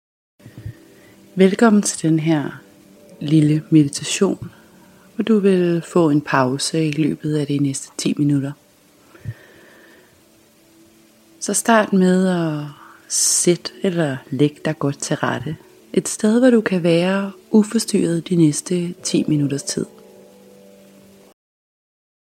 10-13 min. afspændingslydfil hvor jeg vil hjælpe til dig at slappe af og komme i kontakt med din krop. Du kan vælge bare at tage 10 min eller blive liggende i et par min længere, hvor der til sidst er noget rart instrumental musik.